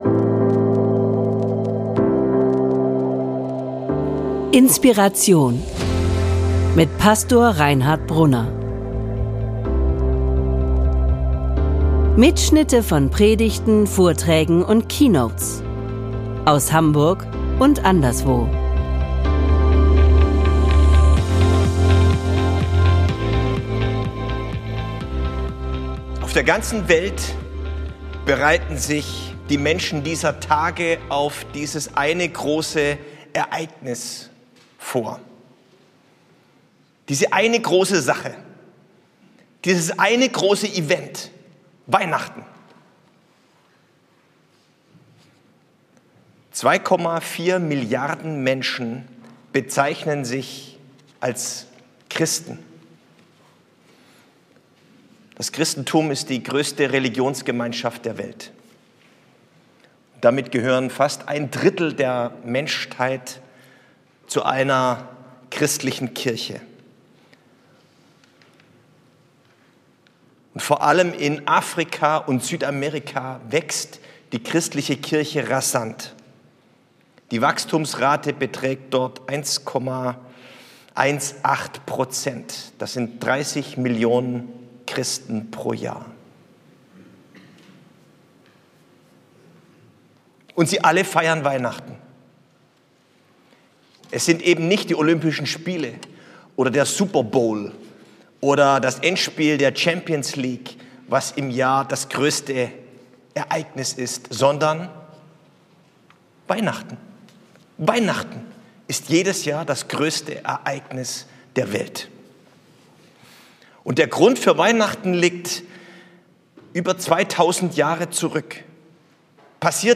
Christvesper 2025 - Warum Weihnachten das größte Ereignis der Weltgeschichte ist?